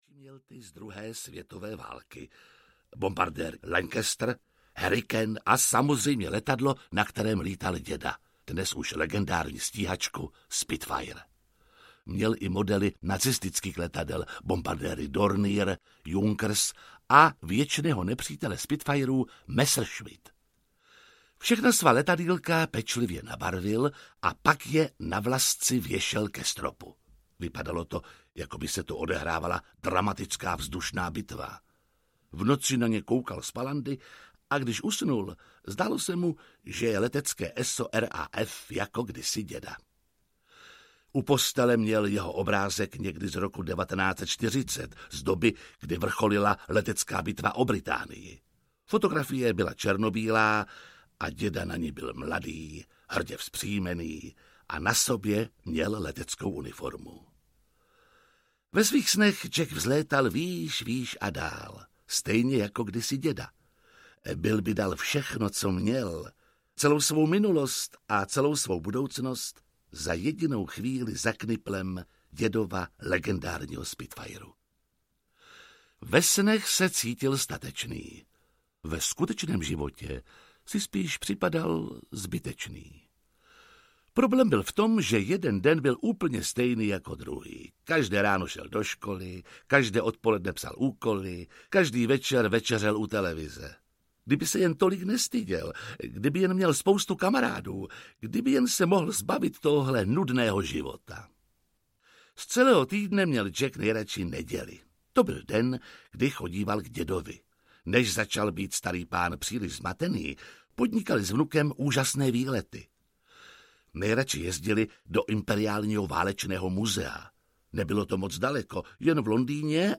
Dědečkův velký útěk audiokniha
Ukázka z knihy
• InterpretJiří Lábus